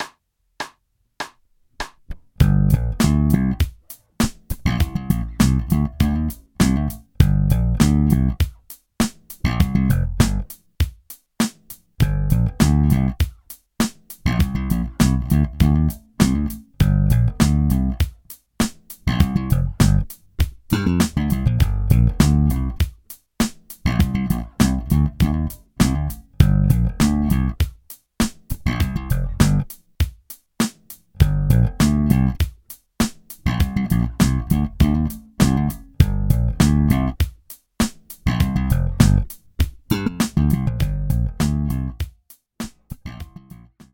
Groove Construction 2 Bass Groove Construction 2